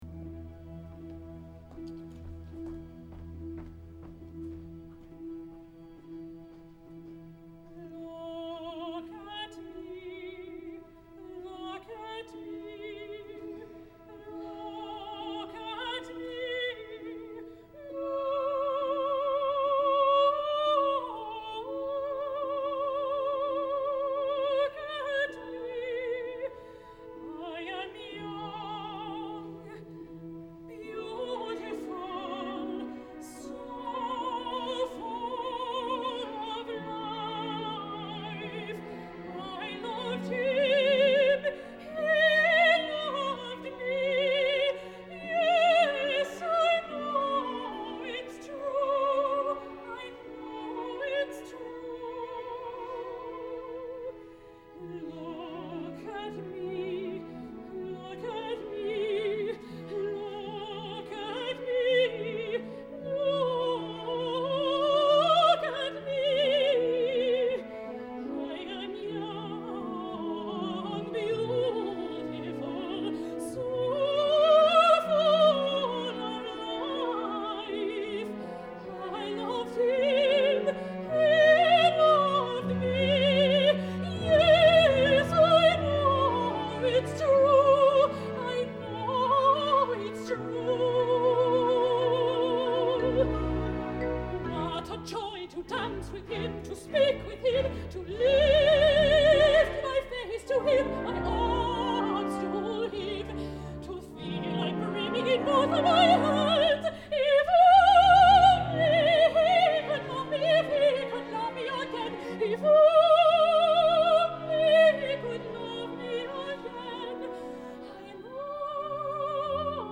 mezzo soprano
In this live performance recording
electro-acoustic orchestral texture